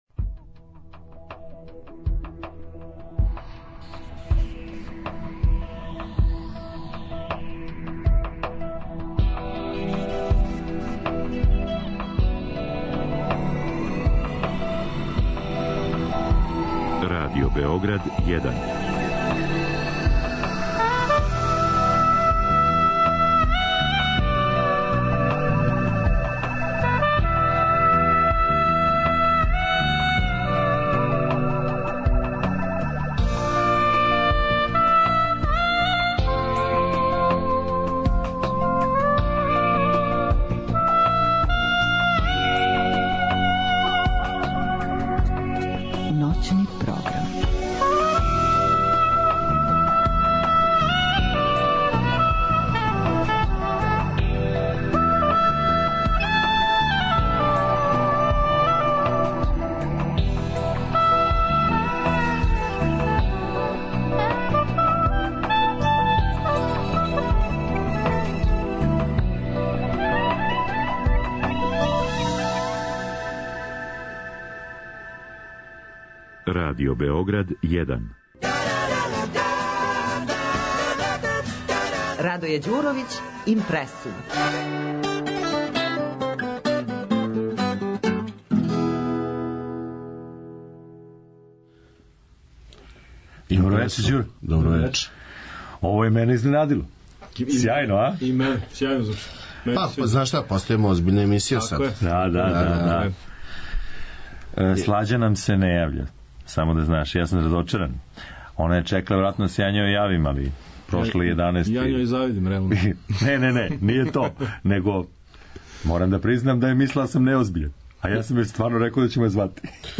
Mini jubilej – 15.-ti IMPRESSUM emitovan je 25. Januara 2014.godine u emisiji „Druga strana računara“ noćnog programa Radio Beograda 1. To je bio dovoljan povod da napišem par redova teksta o tome kako je nastao IMPRESSUM, opšte.